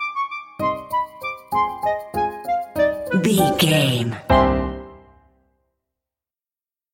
Uplifting
Aeolian/Minor
flute
oboe
strings
circus
goofy
comical
cheerful
perky
Light hearted
quirky